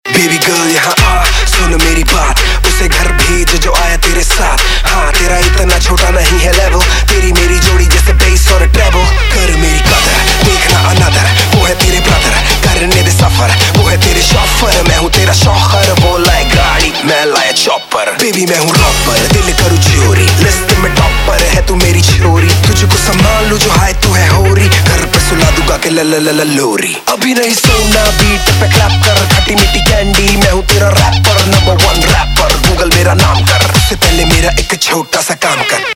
HomeRingtones Mp3 > Indian POP Ringtones